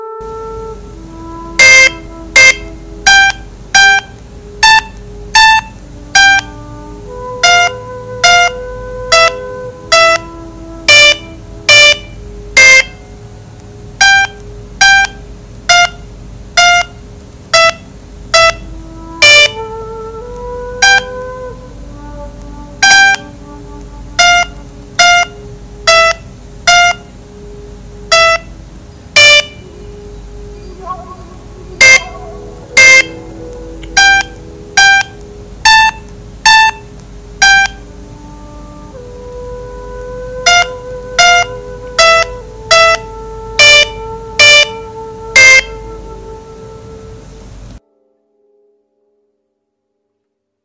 无名琴师演奏.mp3